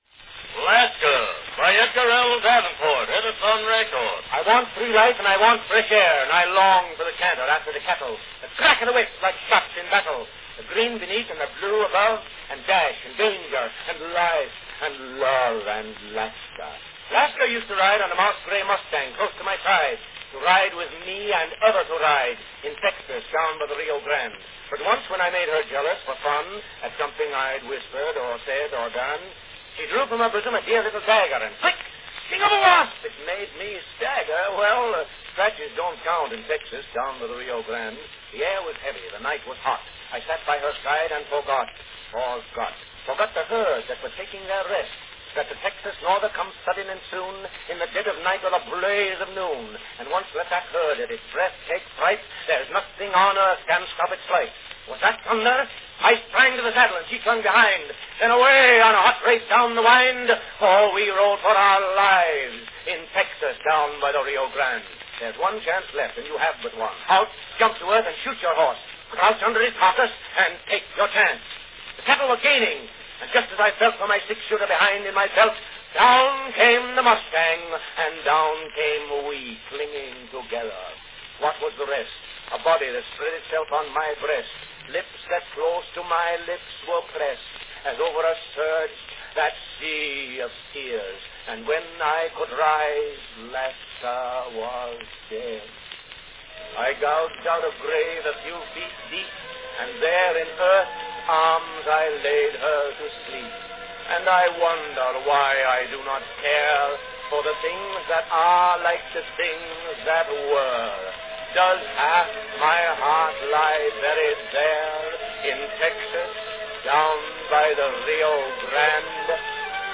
This month:   Perhaps the earliest sound recording of a unique art form: Cowboy poetry.   From 1905, a dramatic two-minute condensation of the cowboy love poem Lasca.
Company Edison's National Phonograph Company
Category Talking
Chopin's Funeral March is introduced at the close of the selection.